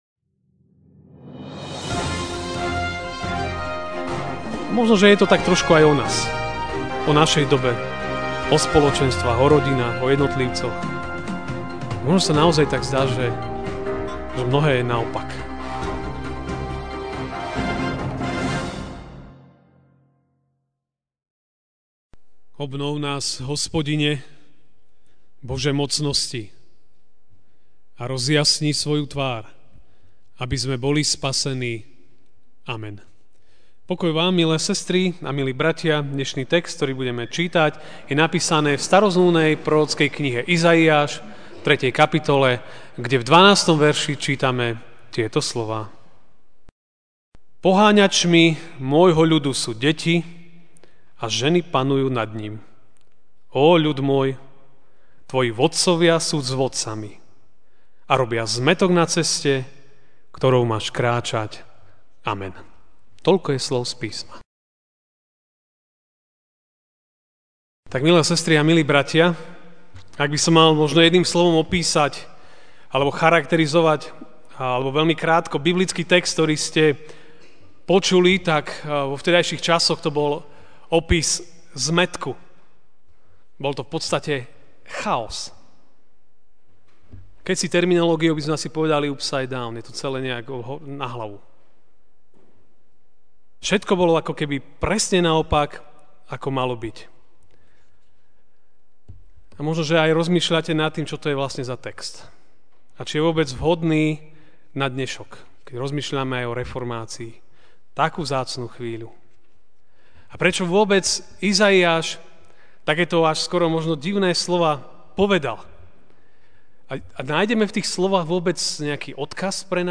Ranná kázeň: Všetko bolo, je(?) a bude naopak? (Izaiáš 3, 12) Poháňačmi môjho ľudu sú deti a ženy panujú nad ním.